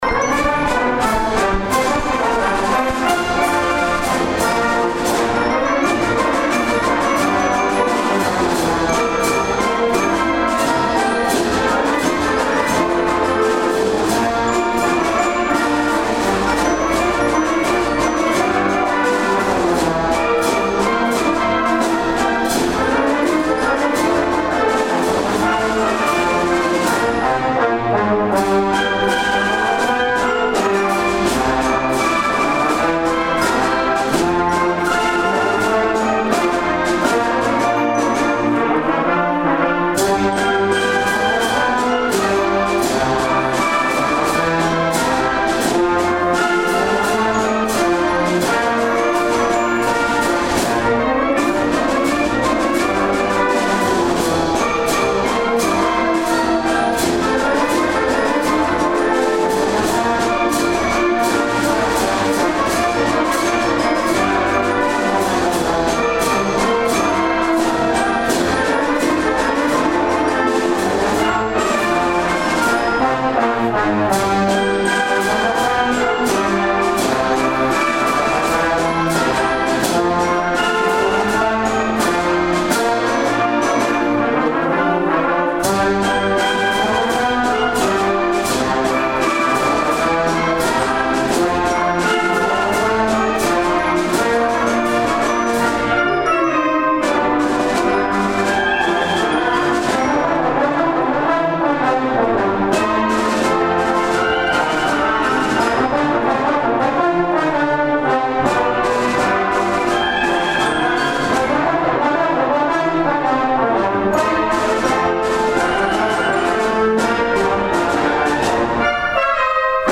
The third and final indoor concert for the 2025 season by the Karl L. King Municipal Band was held on Sunday afternoon, April 27th at 3:30 p.m. in the Fort Dodge Middle School Auditorium.
Kentuky Sunrise, Karl King's two-step Rag was next.